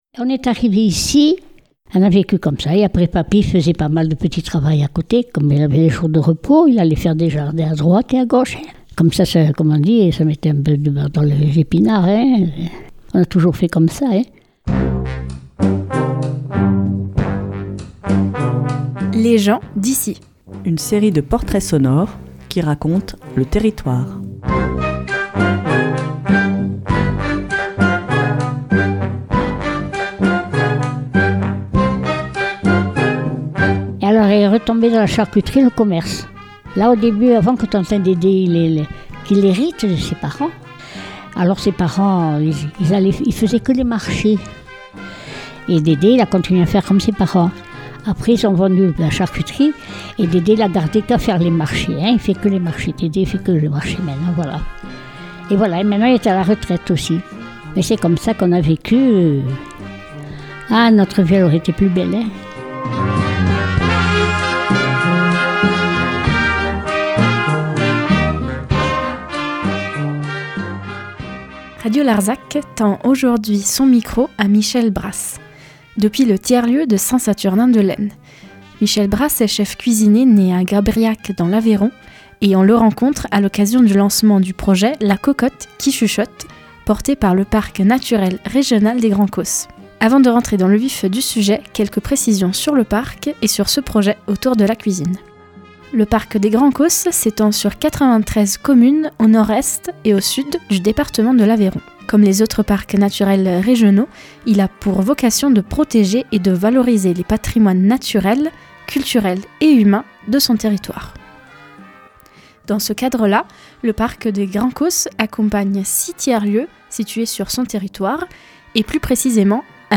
à l’occasion du lancement du projet « La cocotte qui chuchote » porté par le Parc Naturel Régional des Grands Causses.